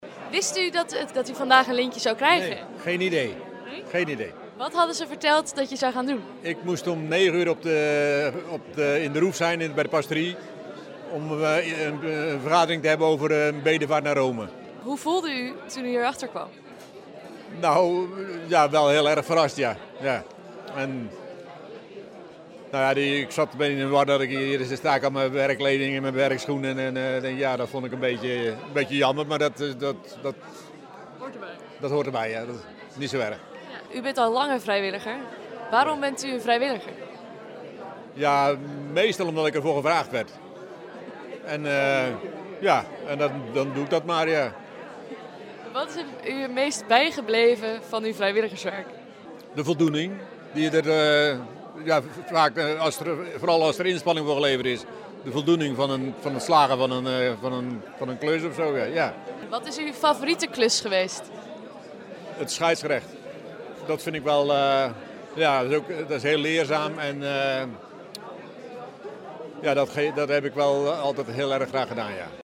Het interview